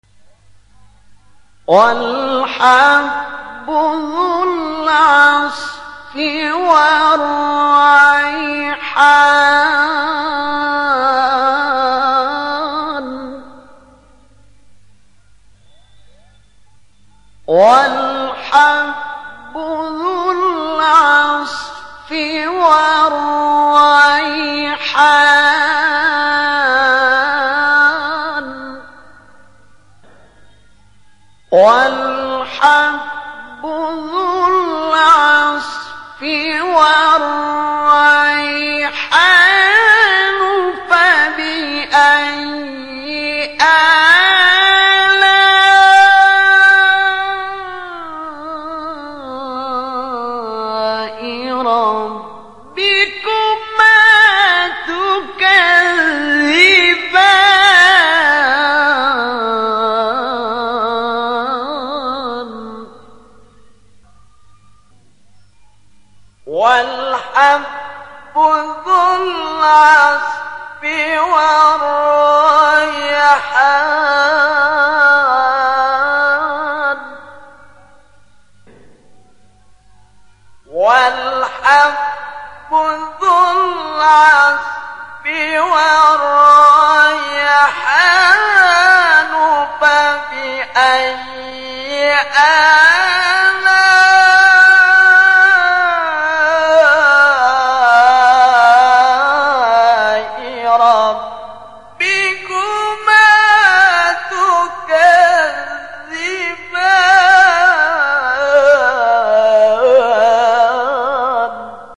بیات-فرعی-شوری-جواب-جواب-2.mp3